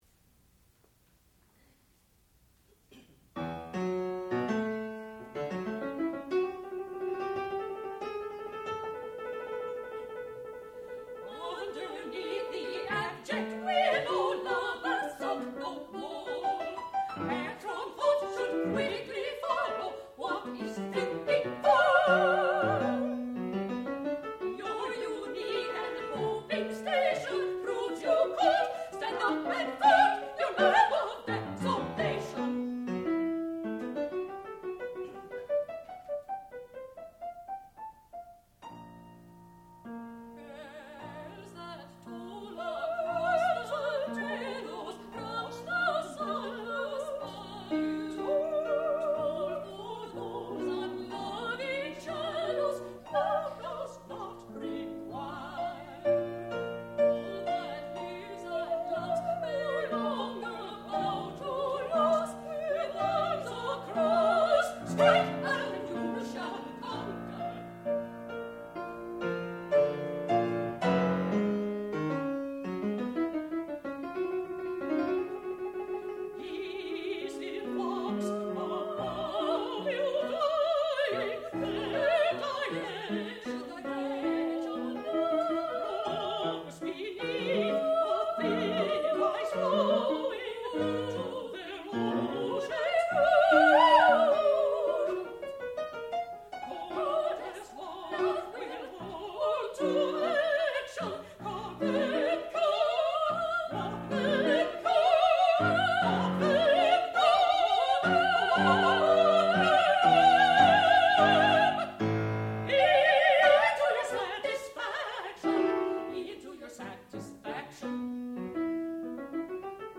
sound recording-musical
classical music
soprano
mezzo-soprano
piano